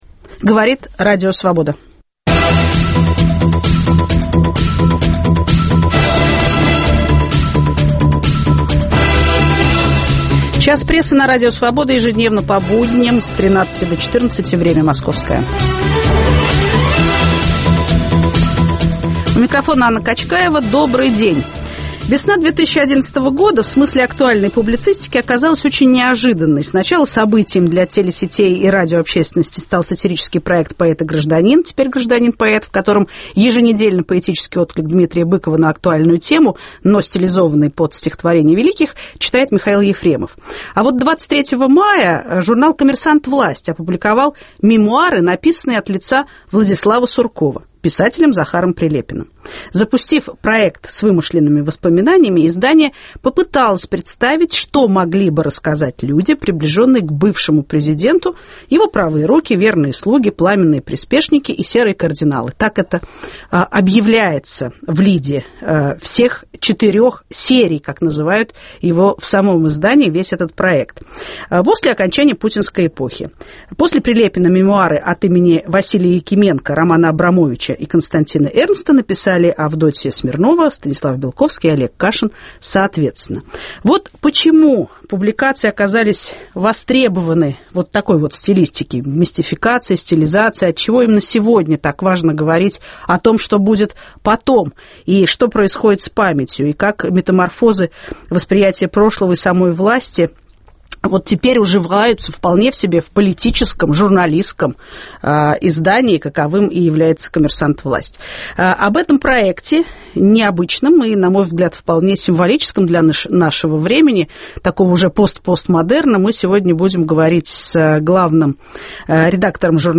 Почему политический журнал "Власть" запустил проект псевдомемуаров людей, определяющих суть и смысл путинской эпохи? Отчего именно сегодня так важно говорить о метаморфозах восприятия прошлого и метаморфозах самой власти? В студии